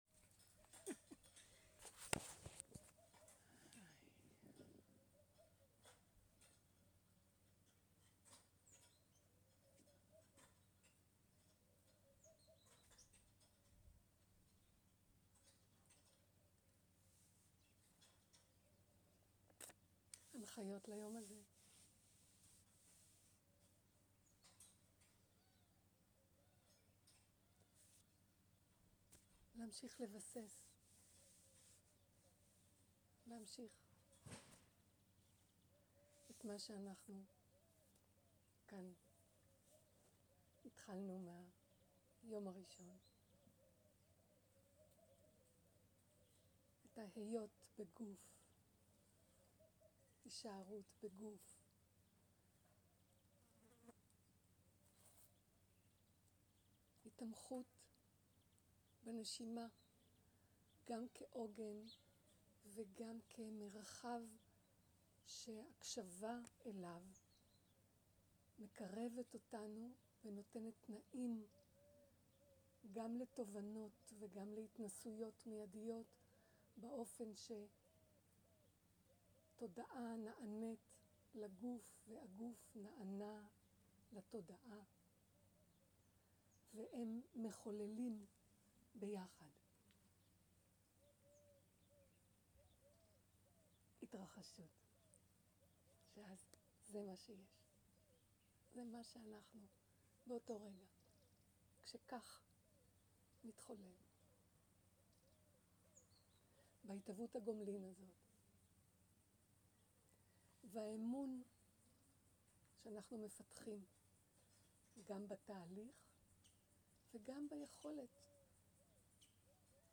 סוג ההקלטה: שיחות דהרמה
איכות ההקלטה: איכות גבוהה